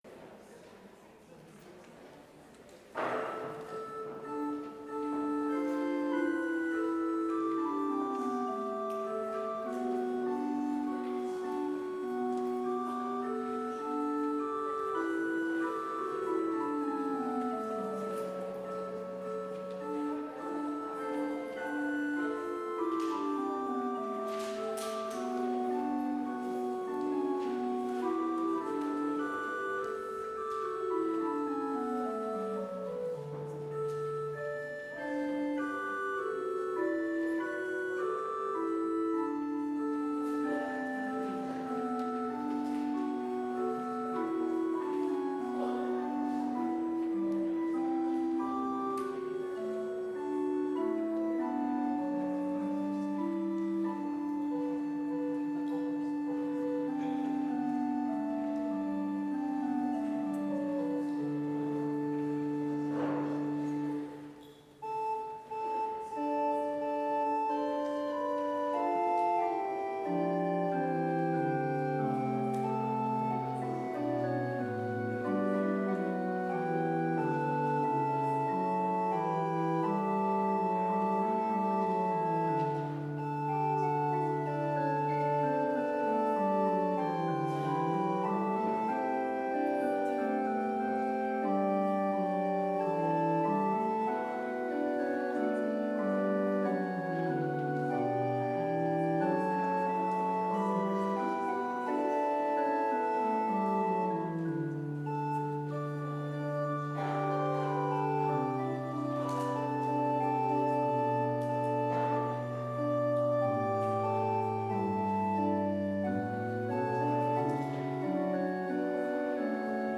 Complete service audio for Chapel - October 28, 2020